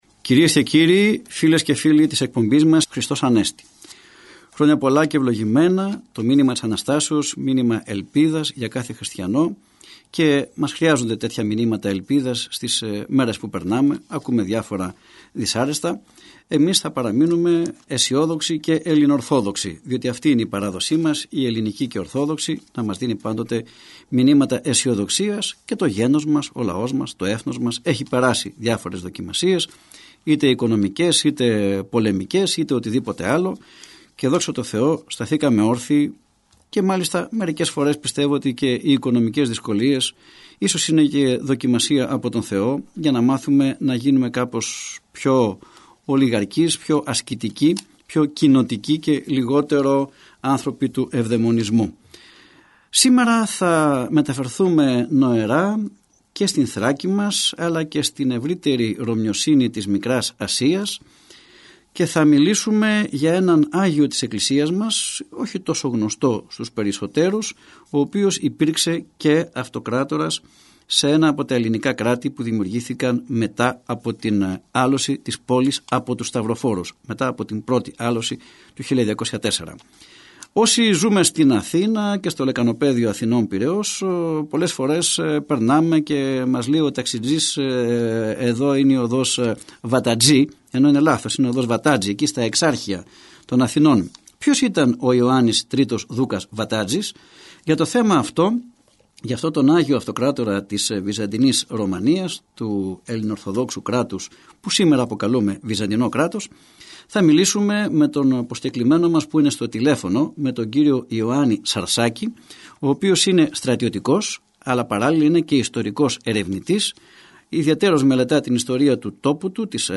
Ακούστε στην συνέχεια, ηχογραφημένα αποσπάσματα της ραδιοφωνικής εκπομπής «Ελληνορθόδοξη πορεία», που μεταδόθηκε από τον ραδιοσταθμό της Πειραϊκής Εκκλησίας.